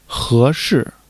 he2-shi4.mp3